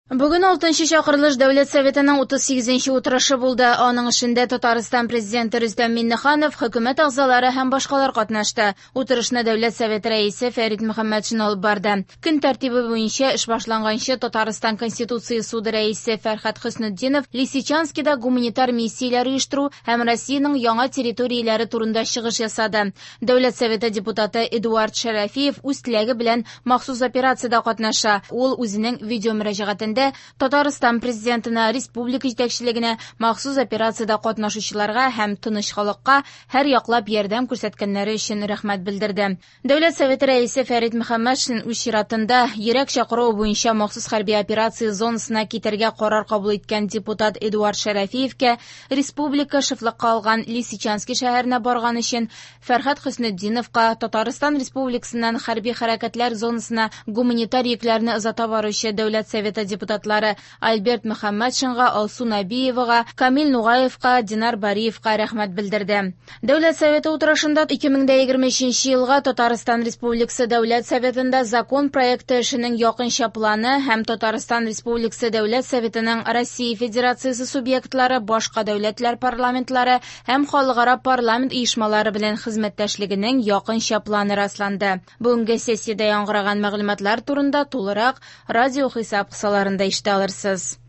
Радиоотчет (15.12.22) | Вести Татарстан